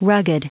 Prononciation du mot rugged en anglais (fichier audio)
Prononciation du mot : rugged